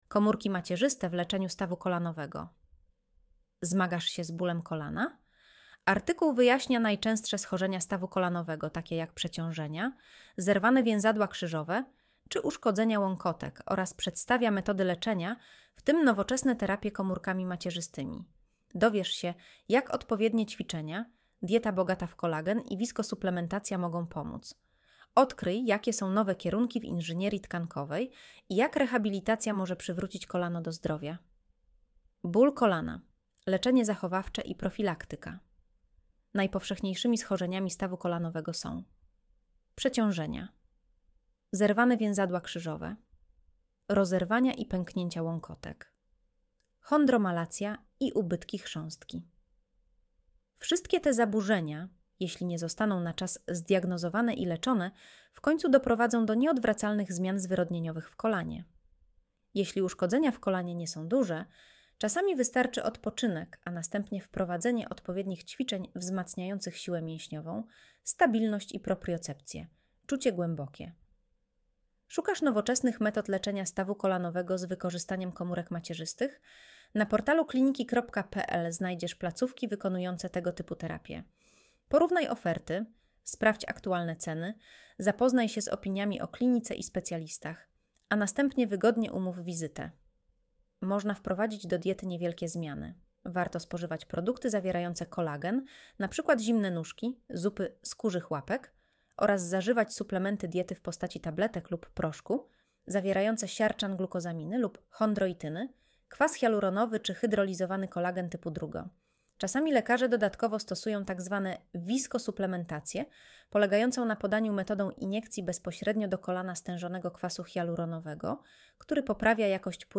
Audio wygenerowane przez AI, może zawierać błędy